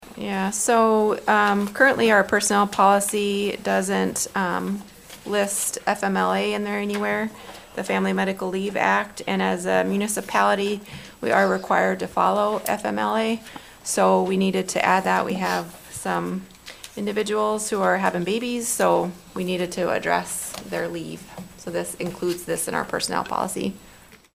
Also at Wednesday’s council meeting: